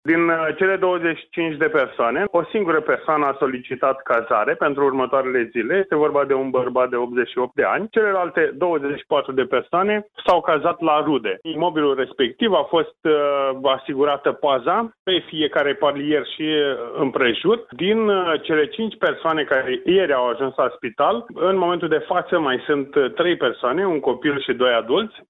Prefectul Dan Șlincu: